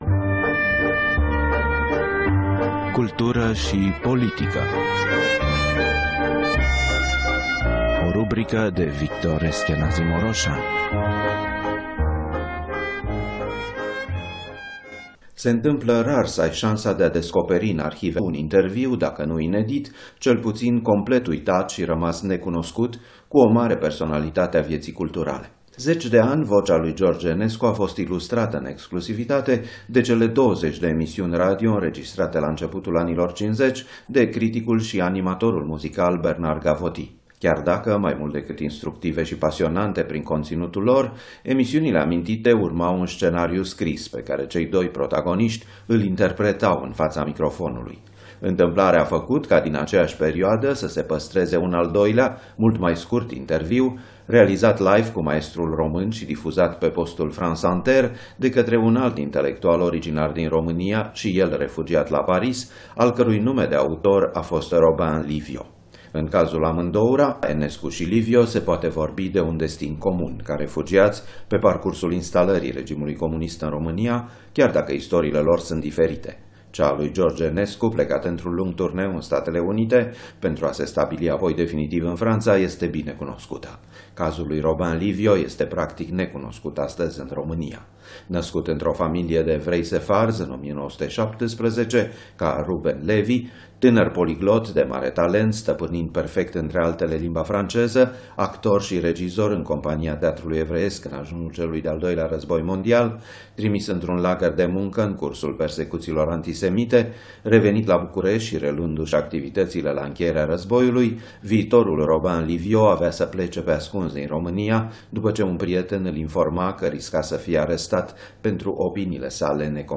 Întîmplarea a făcut ca din aceeași perioadă să se păstreze un al doilea, mult mai scurt, interviu, realizat live cu maestrul român și difuzat pe postul France Inter de către un alt intelectual originar din România
Spre deosebire de emisiunile scenarizate cu Gavoty, aici George Enescu vorbește cu o voce mai așezată, normală pentru o conversație amicală, căutînd uneori expresia cea mai bună, repetînd aproape ca un tic formula franțuzească „n’est pas?” - nu-i așa?